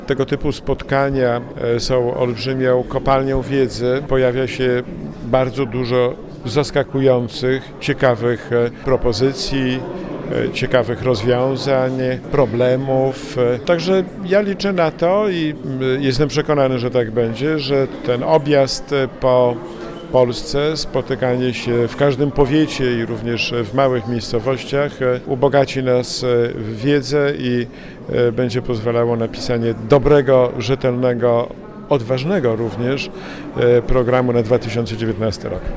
– Doświadczenia z tego cyklu pozwolą nam przygotować program PiS, który będzie odpowiadał potrzebom lokalnej społeczności – mówił Marszałek Senatu reporterowi Radia 5.